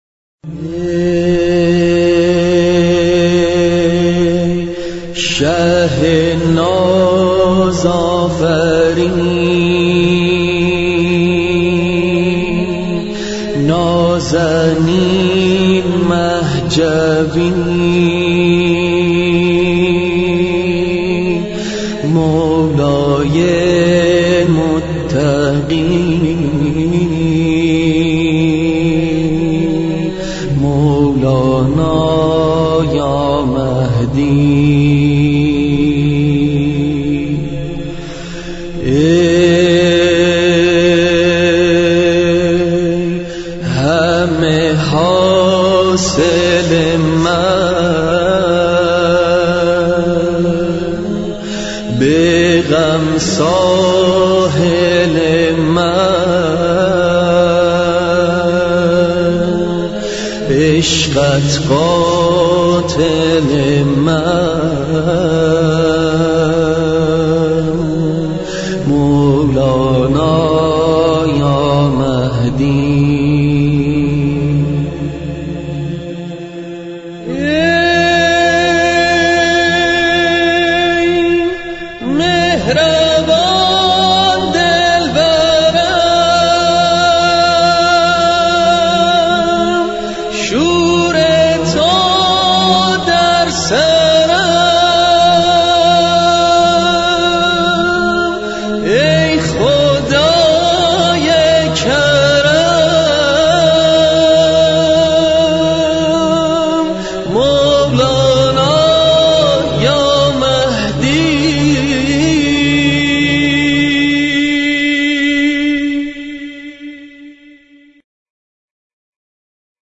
همخوانی مهدوی